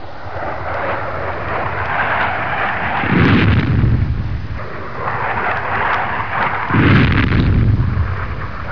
دانلود صدای طیاره 41 از ساعد نیوز با لینک مستقیم و کیفیت بالا
جلوه های صوتی